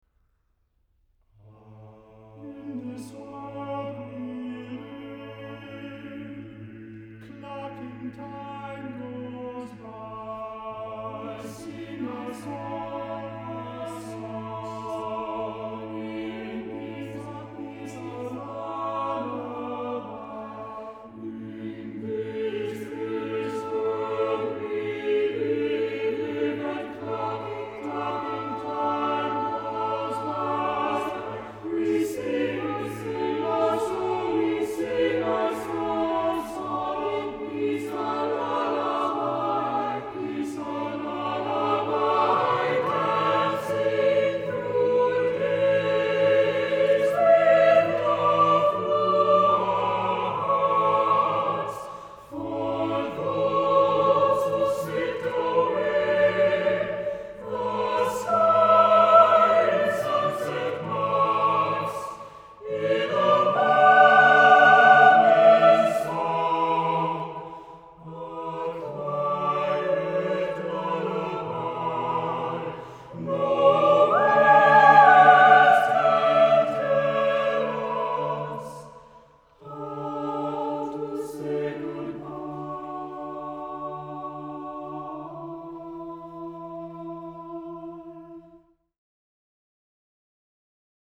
• TTBB chorus (version A)